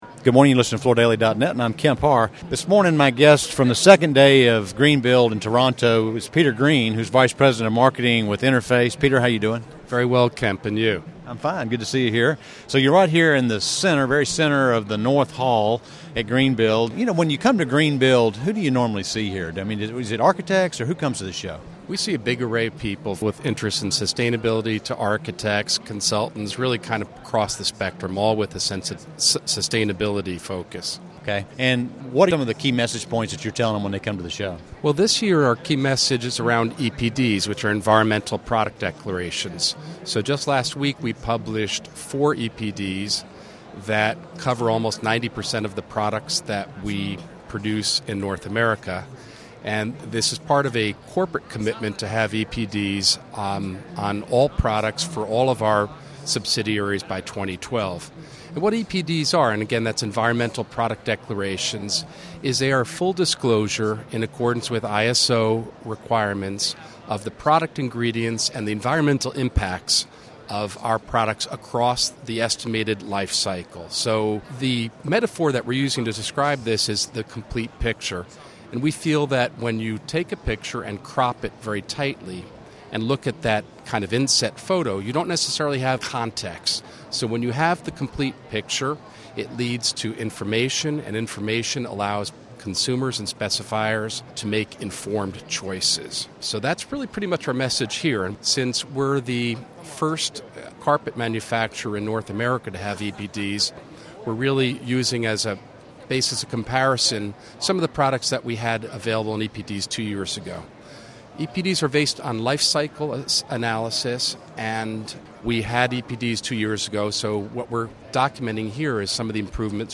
Listen to the interview to find out how EPDs figure into the U.S. Green Building Council's LEED program.
Related Topics:Interface, Greenbuild International Conference and Expo